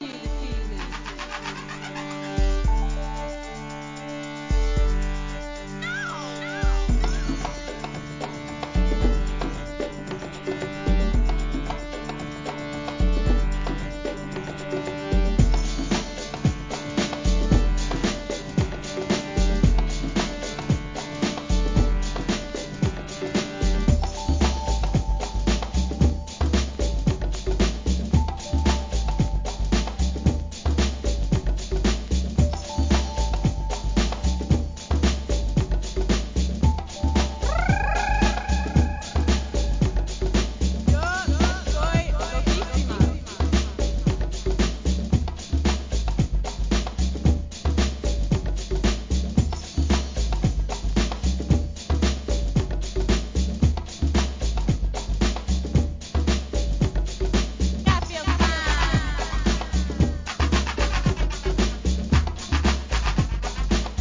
HIP HOP/R&B
ブレイクビーツ